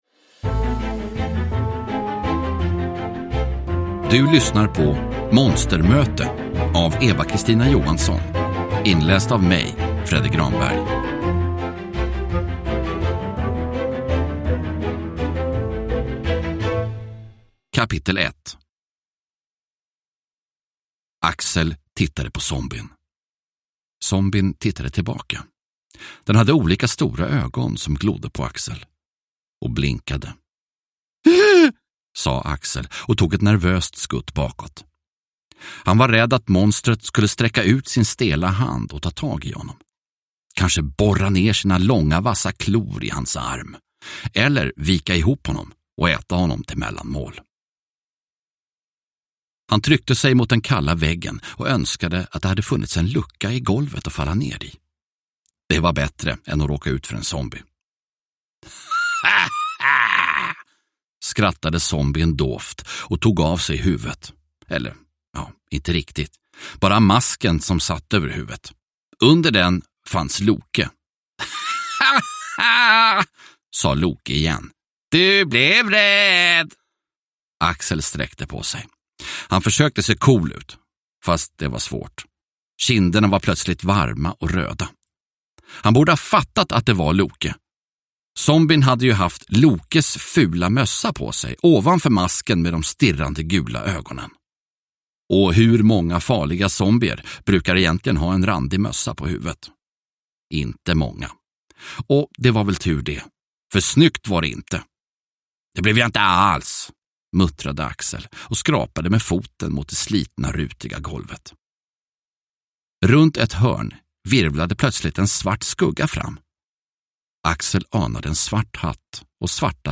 Monstermöte – Ljudbok – Laddas ner